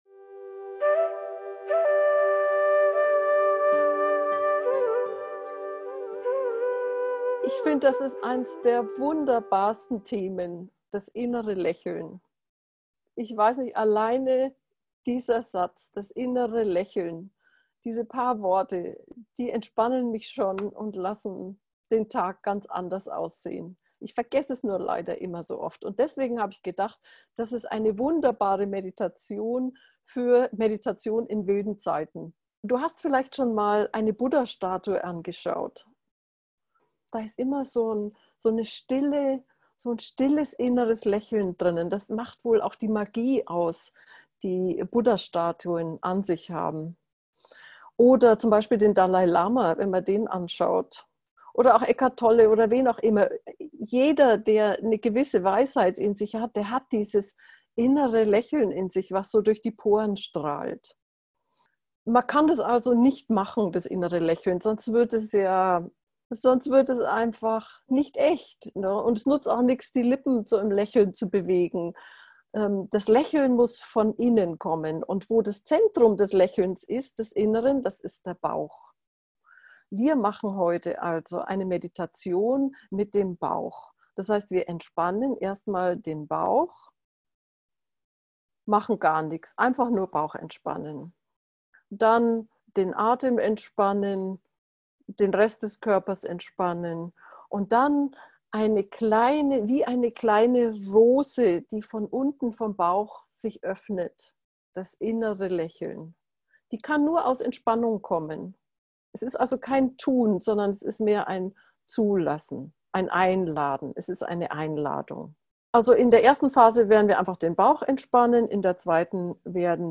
Juni 2020 Meditationsanleitung zur geführten Meditation Schaue auf eine Buddha-Statue und entdecke das innere Lächeln, das weisen Menschen so natürlich ist.